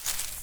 beyond/Assets/ThirdParty/Invector-3rdPersonController/Basic Locomotion/Audio/FootSteps/Grass/grass1.wav at dac9a86e07bf3a64261cc1d1e3587f9a13c71e6a
grass1.wav